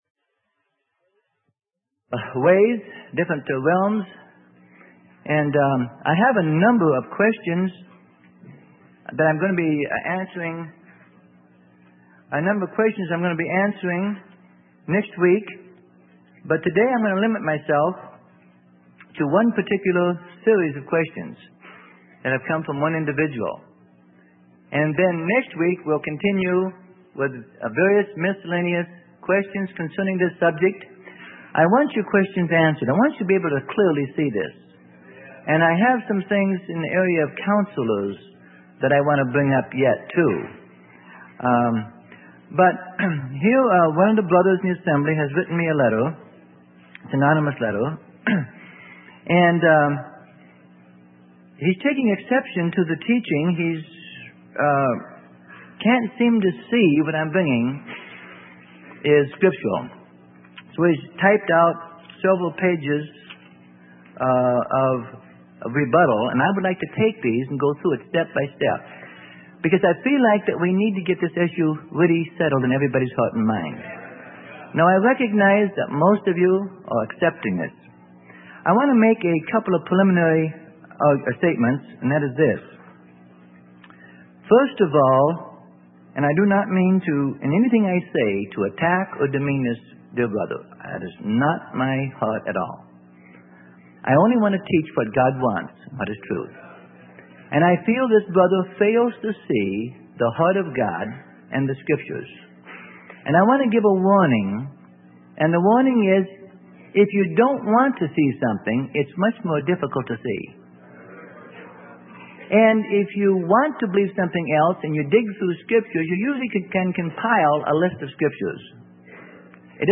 Sermon: The Balance on Authority - Part 3 - Freely Given Online Library